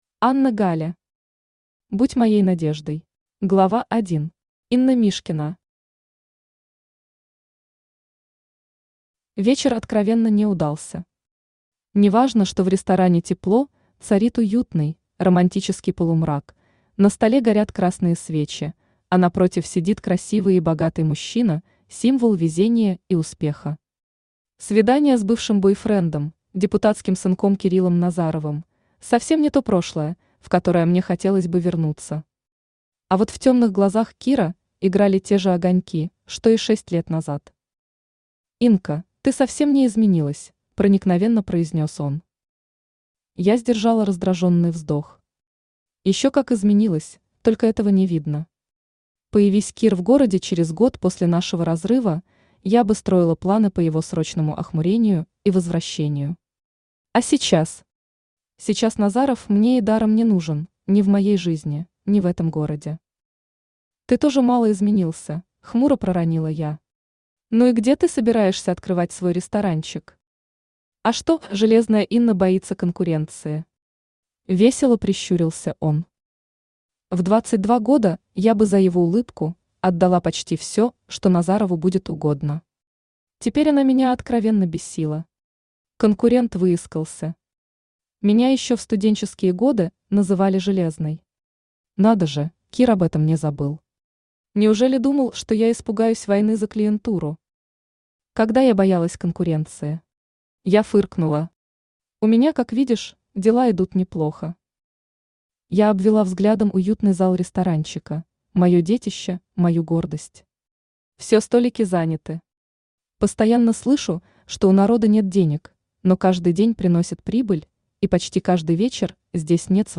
Аудиокнига Будь моей Надеждой | Библиотека аудиокниг
Aудиокнига Будь моей Надеждой Автор Анна Гале Читает аудиокнигу Авточтец ЛитРес.